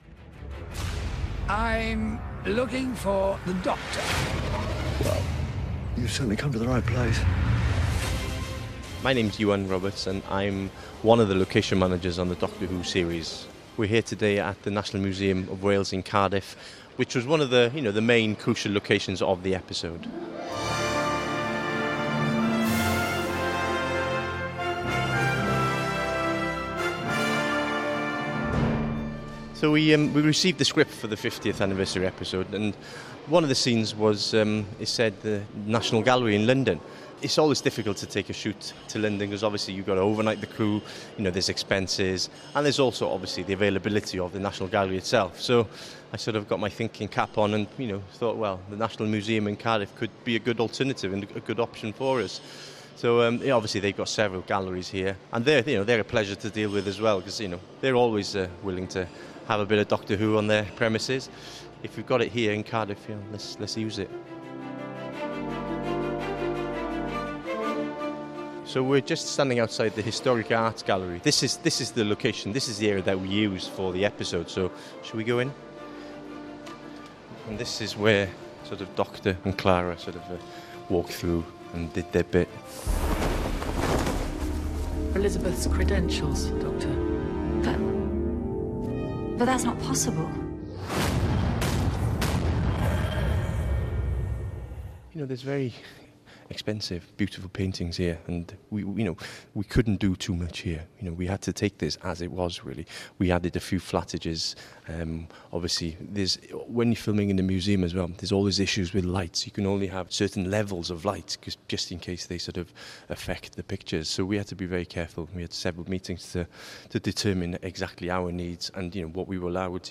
on the set of the anniversary special.